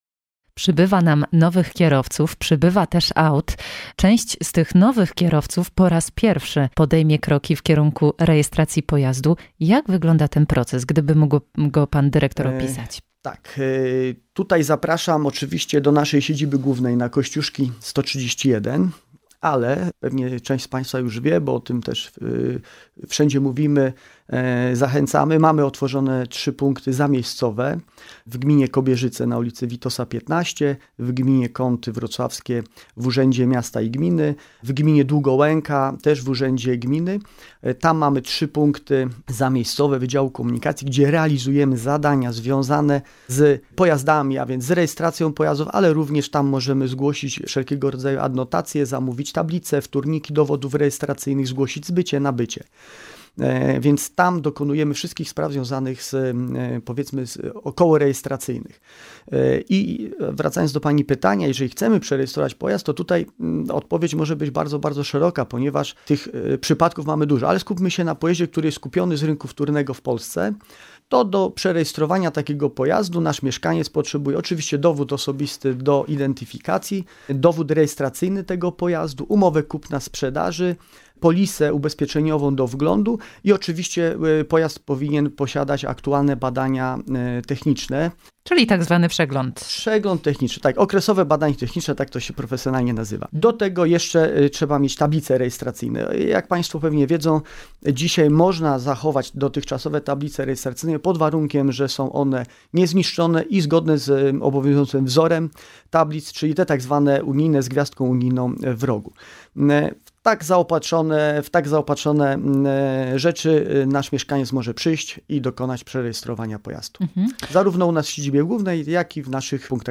Rozmowa na antenie w czwartek 30 listopada po godz. 14:10.